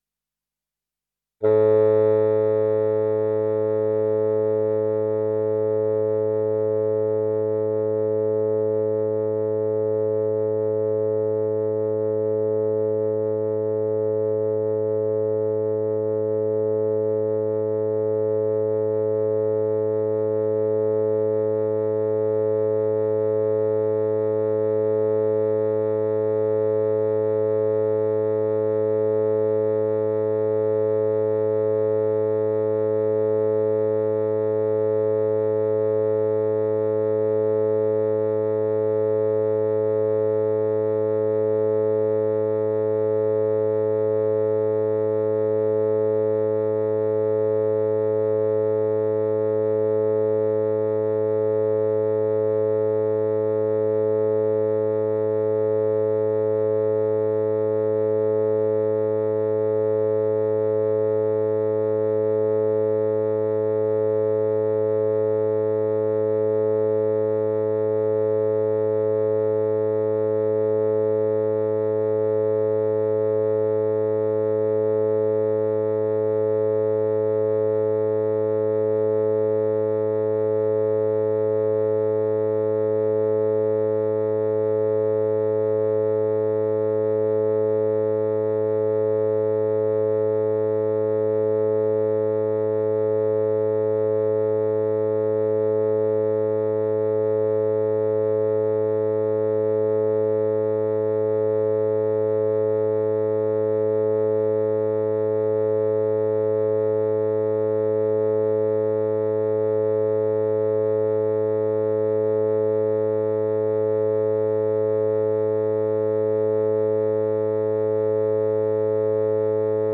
LA_432_Hz_Oboe.mp3